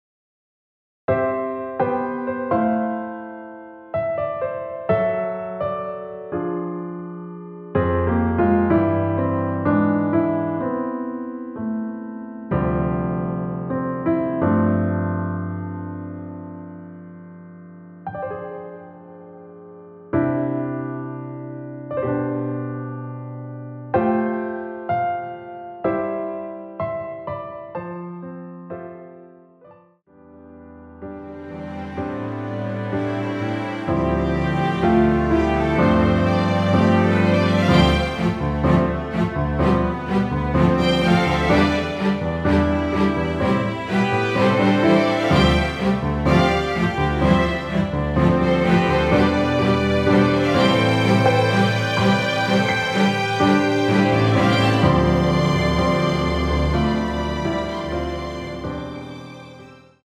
원키에서(-1)내린 MR입니다.
앞부분30초, 뒷부분30초씩 편집해서 올려 드리고 있습니다.
중간에 음이 끈어지고 다시 나오는 이유는